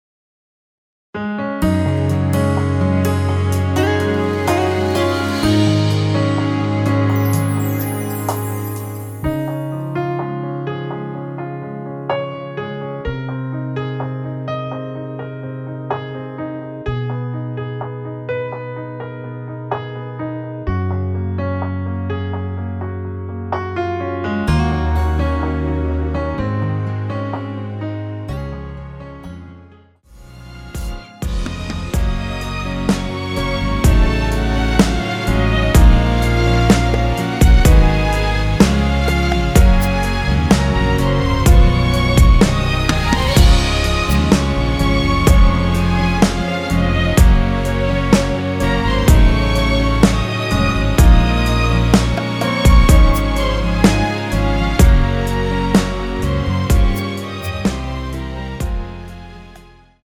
원키에서(-2)내린 MR입니다.
Db
앞부분30초, 뒷부분30초씩 편집해서 올려 드리고 있습니다.
중간에 음이 끈어지고 다시 나오는 이유는